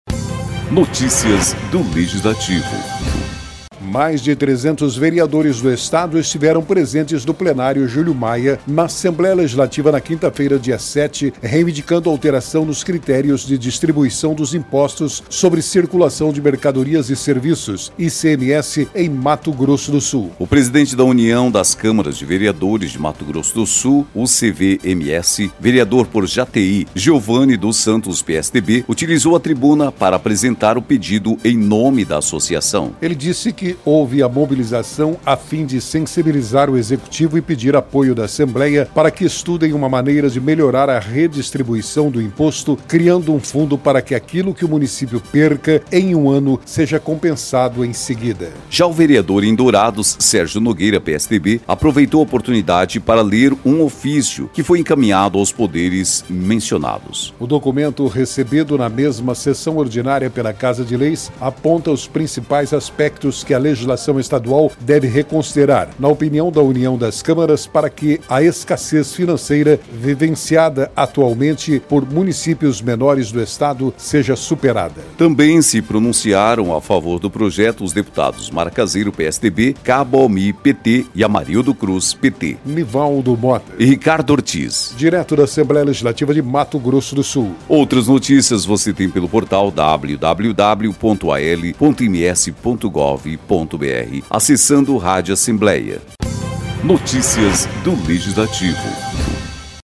Mais de 300 vereadores do Estado estiveram presentes no Plenário Júlio Maia, na Assembleia Legislativa, nesta quinta-feira (07/12), reivindicando alteração nos critérios de distribuição do Imposto sobre Circulação de Mercadorias e Serviços (ICMS) em Mato Grosso do Sul.